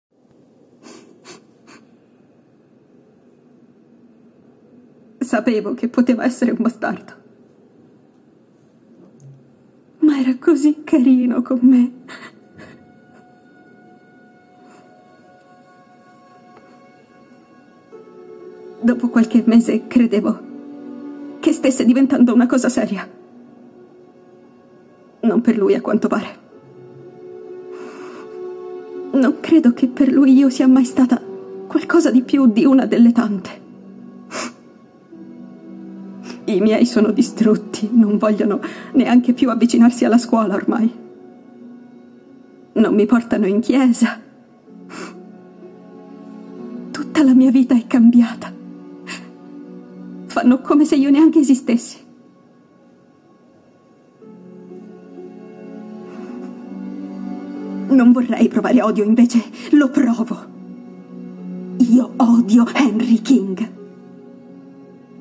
nel telefilm "Stargirl", in cui doppia Yvette Monreal.